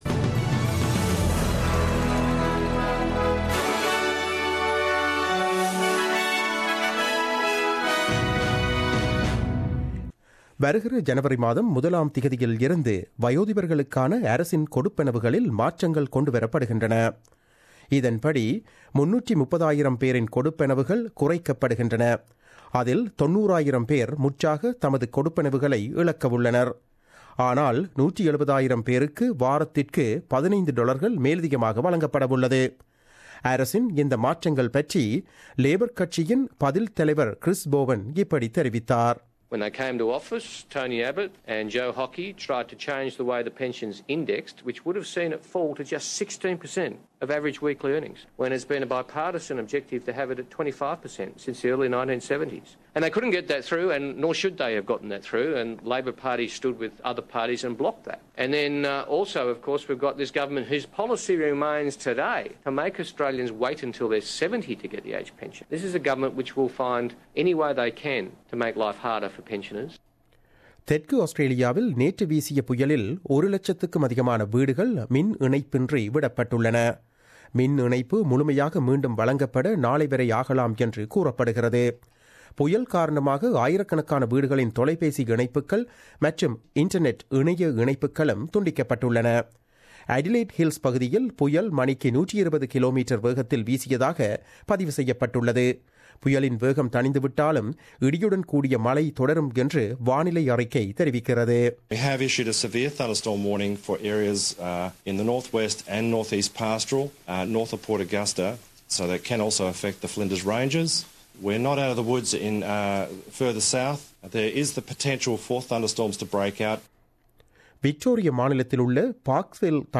The news bulletin aired on 28 December 2016 at 8pm.